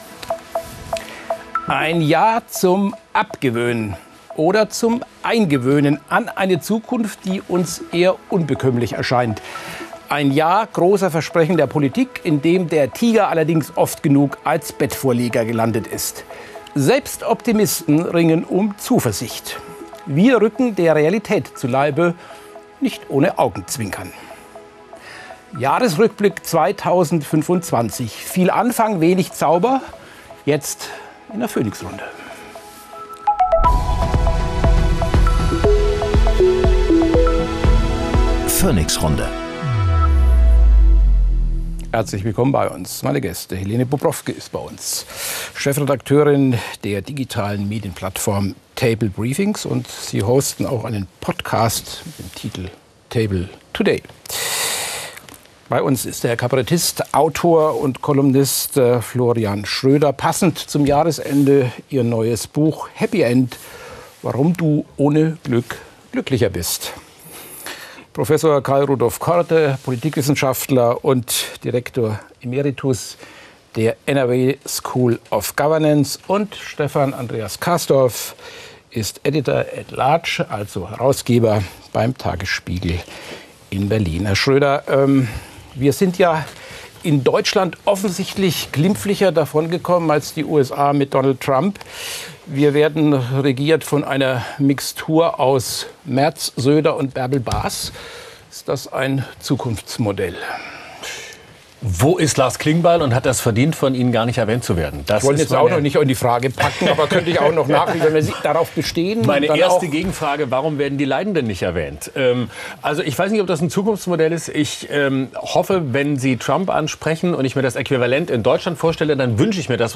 Florian Schroeder, Kabarettist
Prof. Karl-Rudolf Korte, Politikwissenschaftler